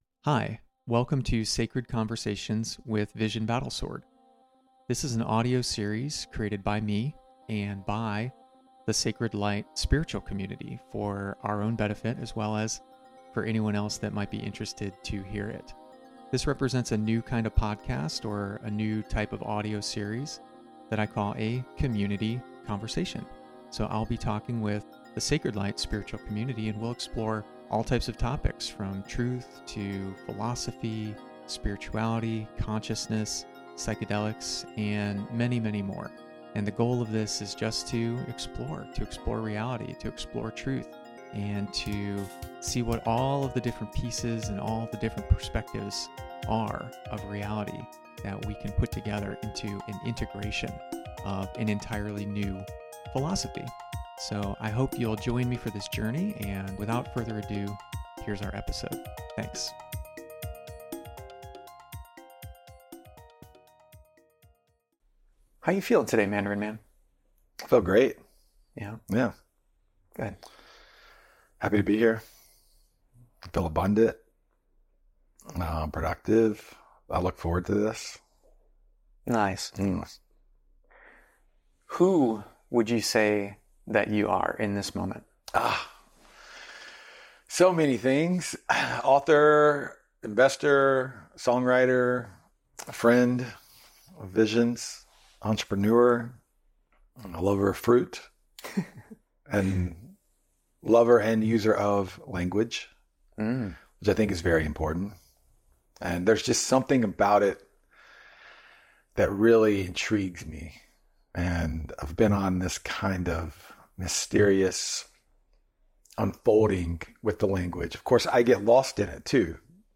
conversation21-language.mp3